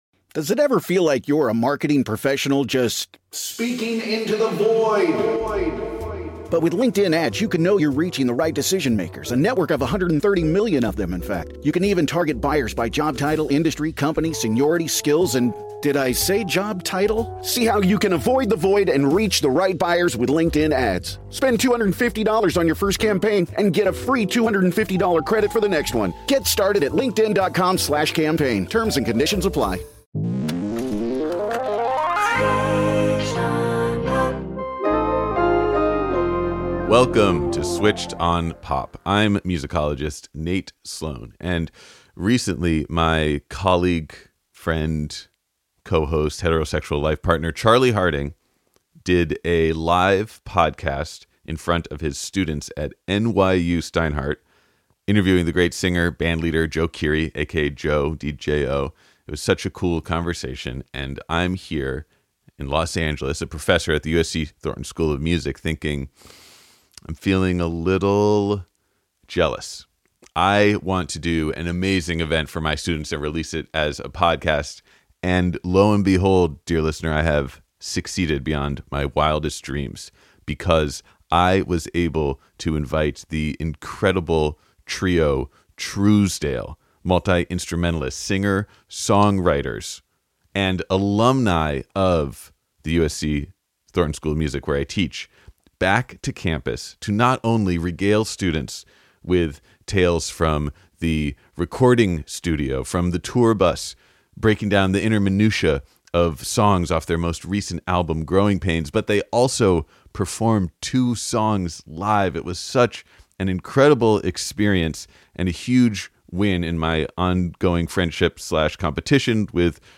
The Power of the Trio (ft. Trousdale live at USC)
There's no lead singer in Trousdale.
Since then, they've touring the world and released a sophomore album, Growing Pains, that features the trio's impeccable harmonies over 70s-inflected country-rock grooves.